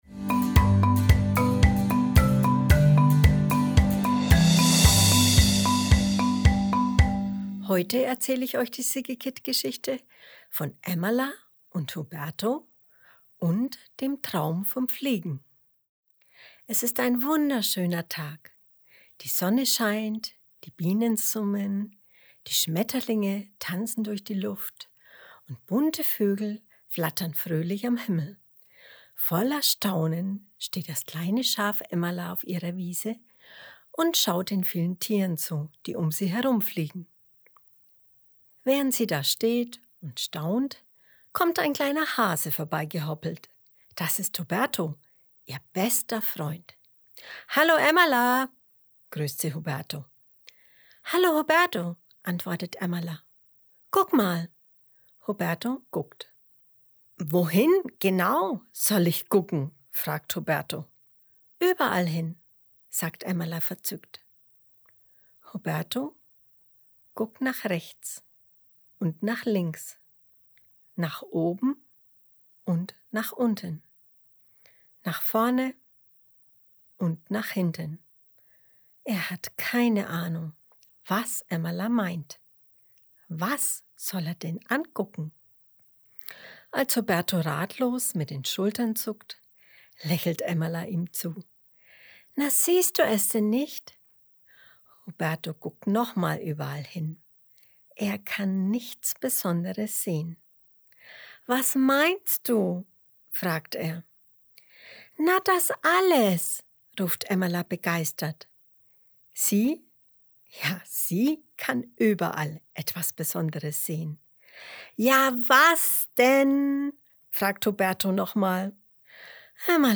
April 2022 Kinderblog Vorlesegeschichten, Emmala & Huberto Schäfchen Emmala träumt davon, wie schön es wäre, zu fliegen.